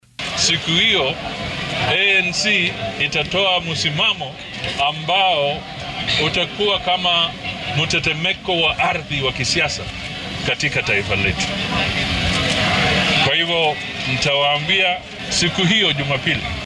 Musalia Mudavadi oo shalay warbaahinta kula hadlay ismaamulka Nyamira ayaa ka gaabsaday inuu faahfaahiyo qodobbada shirka NDC diiradda lagu saari doono.